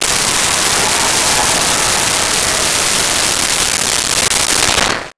Large Crowd Applause 05
Large Crowd Applause 05.wav